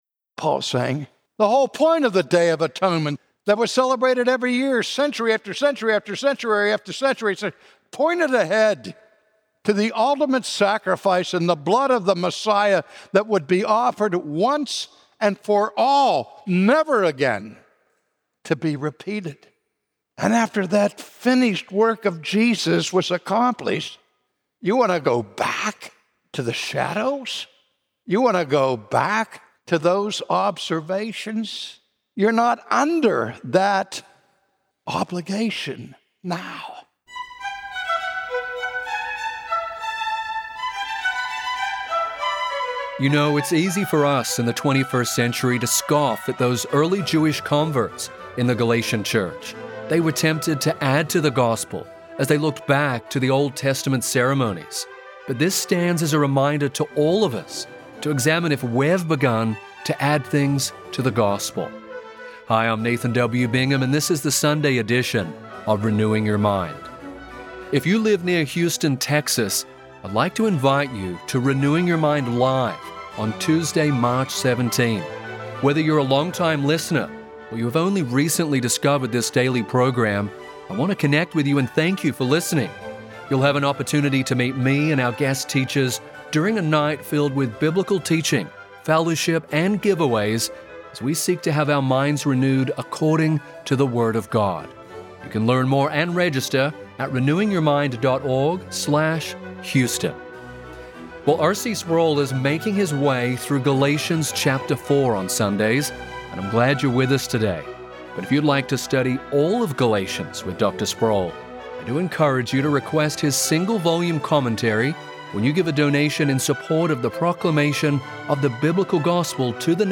The Old Testament ceremonies and sacrifices pointed to Christ. To return to them now would be a denial of Jesus' finished work of redemption. From his sermon series in Galatians